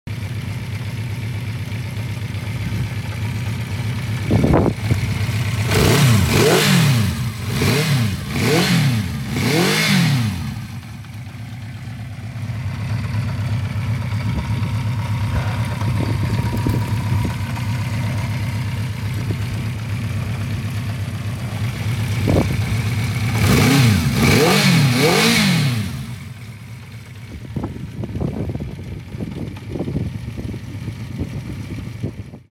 What a fantastic sound this Honda produces! WIN this classic CB400 Four + £500 in cash for as little as £2.99!